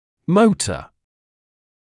[‘məutə][‘моутэ]двигатель, мотор; «движущая сила»; двигательный, моторный